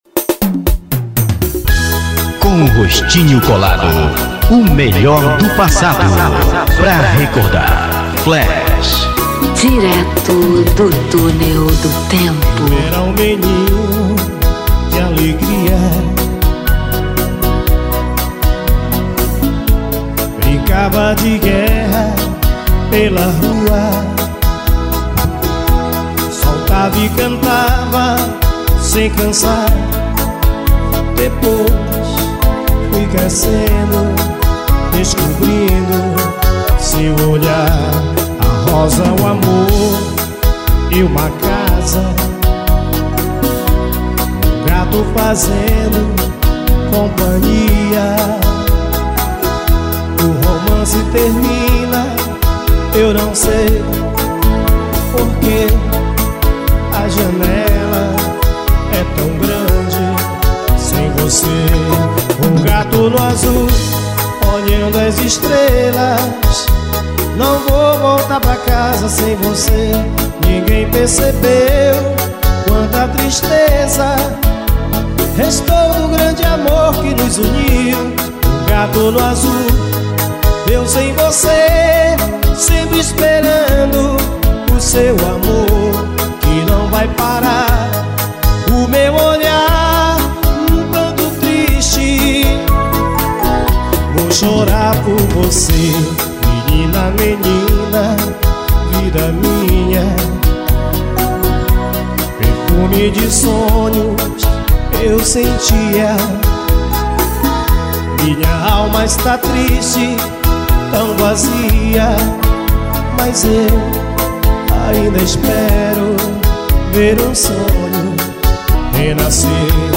AO VIVO NO CATA CORNO MANAUS.